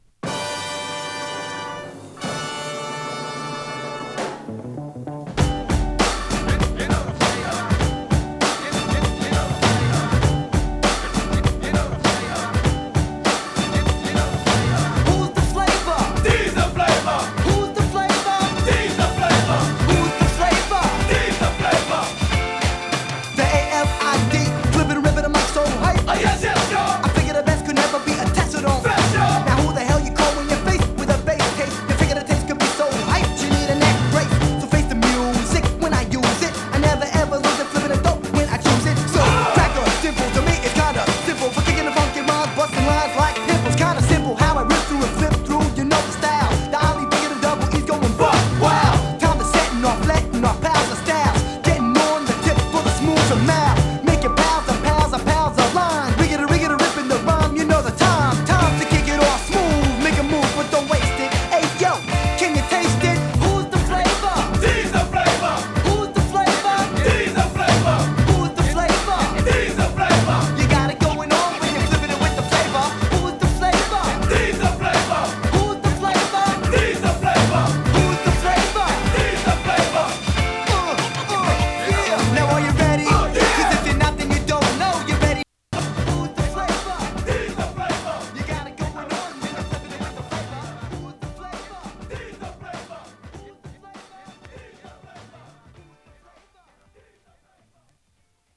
合唱必至なパーティー・ミドル・クラシック！
Vocal 3.48